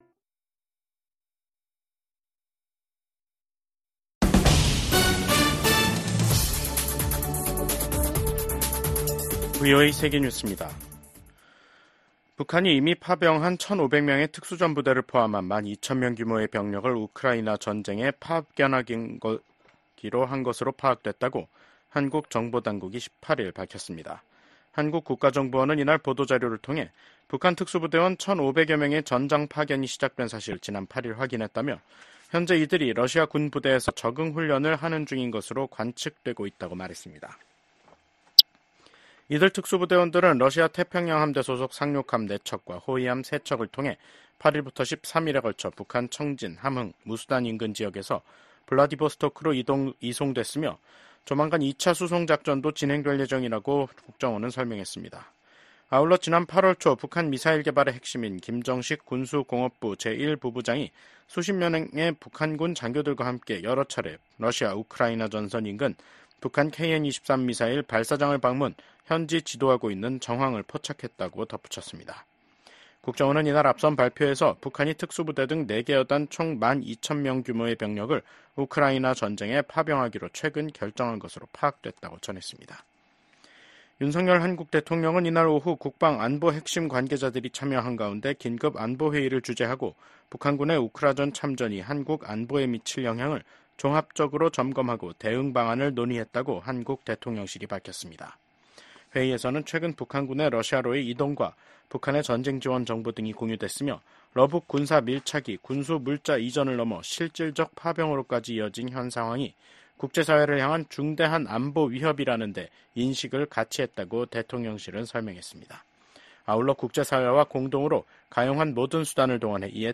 VOA 한국어 간판 뉴스 프로그램 '뉴스 투데이', 2024년 10월 18일 2부 방송입니다. 북한이 한국을 헌법상 적대국으로 규정한 가운데 김정은 국무위원장은 전방부대를 방문해 한국을 위협하는 행보를 보였습니다. 윤석열 한국 대통령은 국가안보실, 국방부, 국가정보원 핵심 관계자 등이 참석한 가운데 ‘북한 전투병의 러시아 파병에 따른 긴급 안보회의’를 열어 대응 방안을 논의했다고 대통령실이 전했습니다.